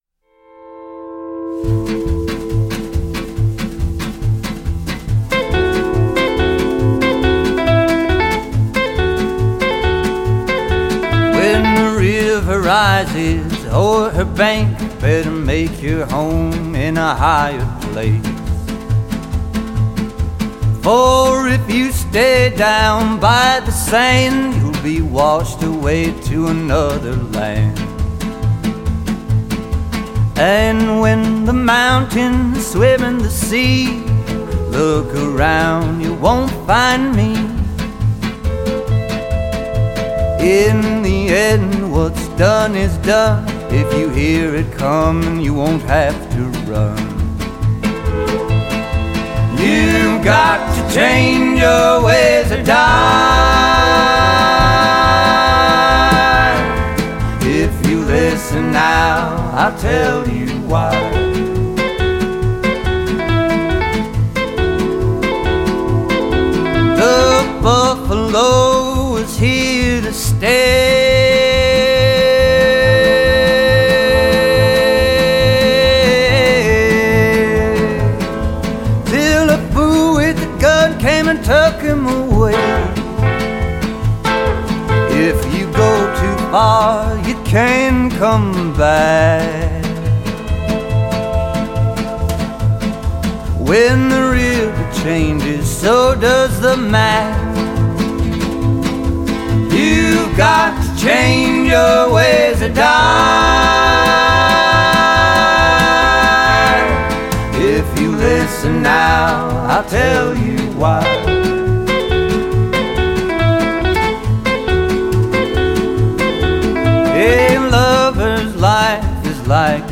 дуэт